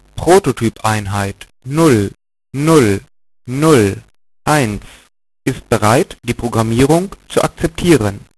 mit dem Cabaret-Codec (31250 bit/s) komprimiert und anschließend dekomprimiert